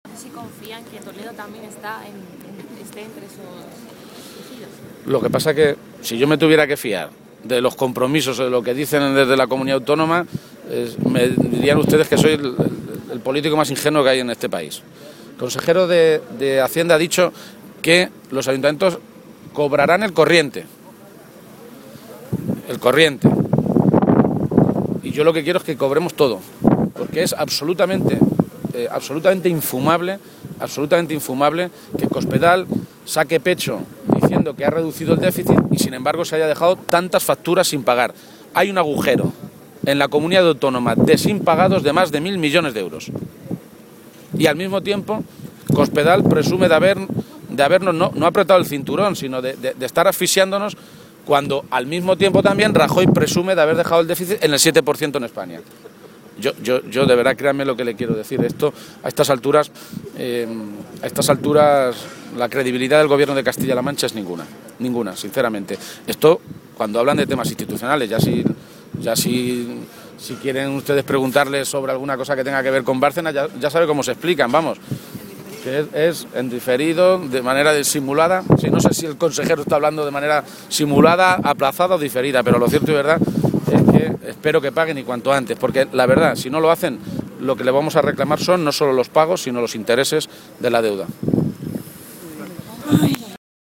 Emiliano García-Page, Secretario General del PSOE de Castilla-La Mancha
García-Page se pronunciaba de esta manera a preguntas de los periodistas y añadía que “resulta muy grave y muy sospechoso que un Gobierno que denuncia una supuesta actuación del anterior Gobierno, en vez de ponerlo en conocimiento de la Policía o la Guardia Civil, lo derive a una agencia de detectives”.
Cortes de audio de la rueda de prensa